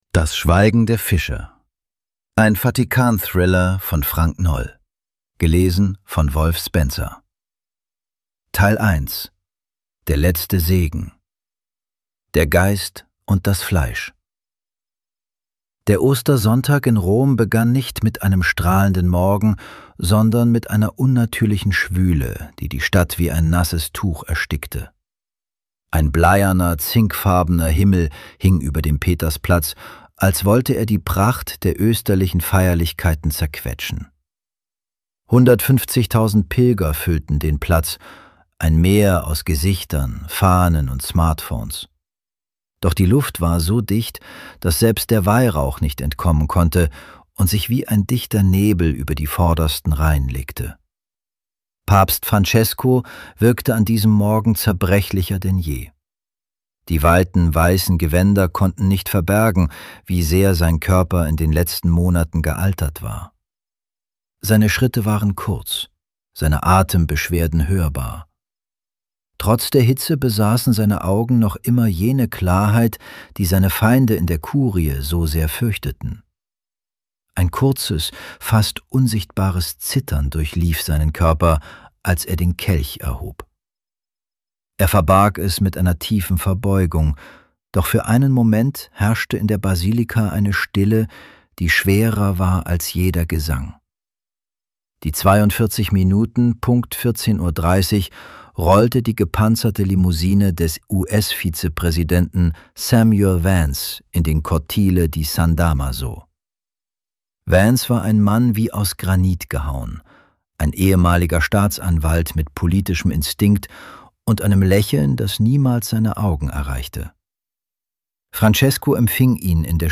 Das Schweigen der Fischer. Ein Vatikan-Thriller von Frank Noll | Audiobook | 63 min
(ElevenLabs)